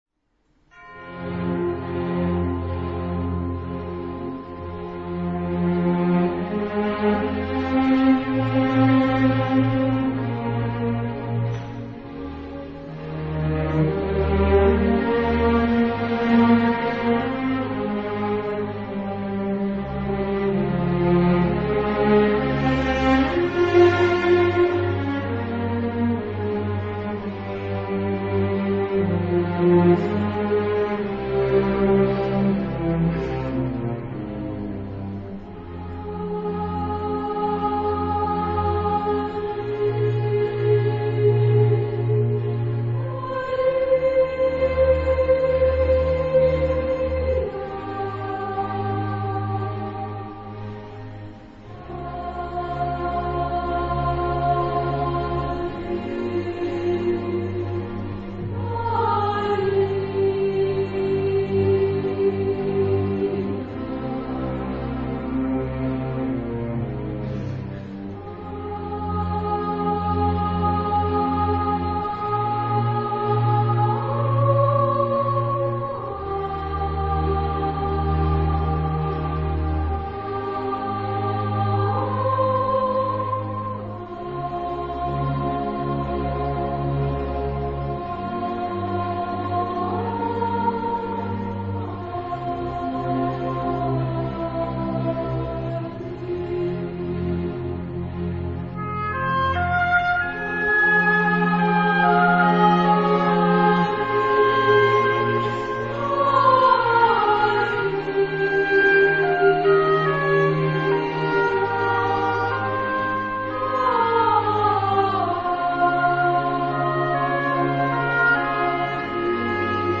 a clevelandi Szent Imre Katolikus Templomból.